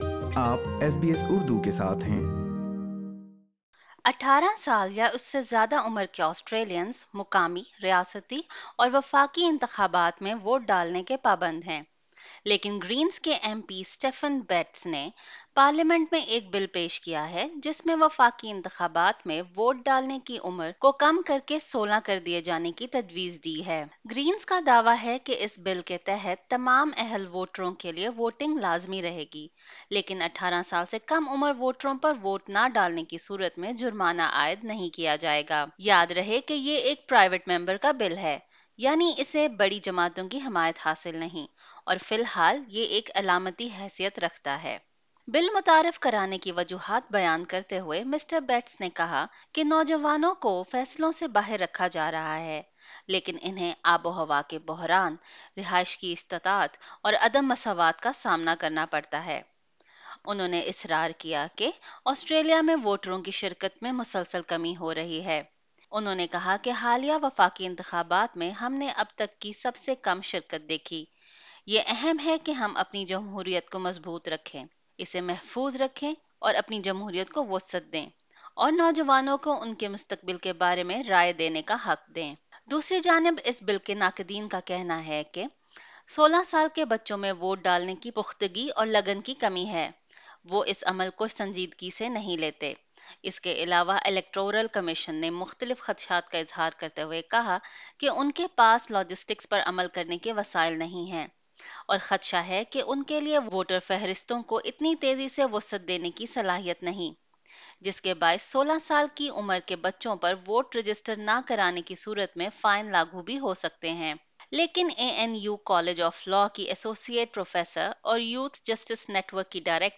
18 سال یا اس سے زیادہ عمر کے آسٹریلینز مقامی، ریاستی اور وفاقی انتخابات میں ووٹ ڈالنے کے پابند ہیں، لیکن گرینز کے ایم پی اسٹیفن بیٹس نے پارلیمنٹ میں ایک بل پیش کیا ہے جس میں وفاقی انتخابات میں ووٹ ڈالنے کی عمر کو کم کر کے 16 کر دیے جانے کی تجویز دی گئی ہے۔ آسٹریلیا میں اس قانون کی اہمیت کے بارے میں بات کرنے کیلیے ایس بی ایس اردو نے ایسے والدین سے بات کی ہے جن کے بچوں کی عمر 16 سال کے لگ بگ ہے۔